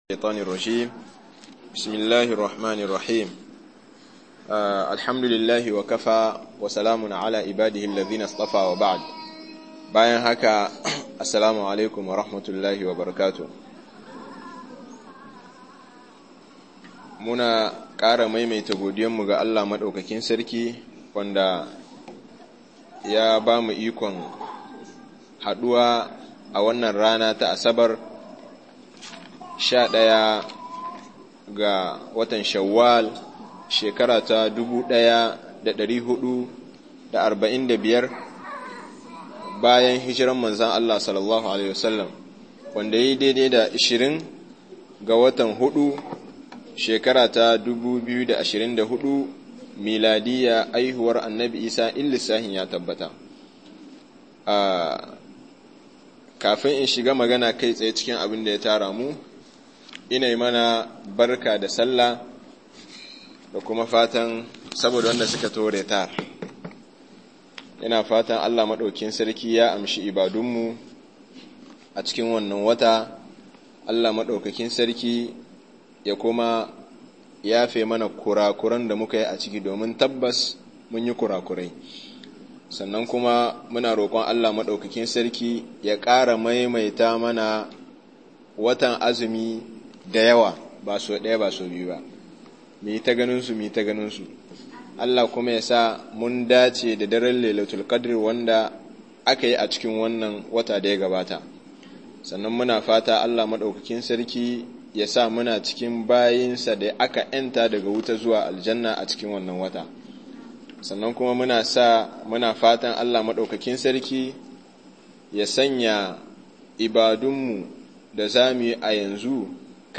biyayya ga iyaye-01 - Muhadara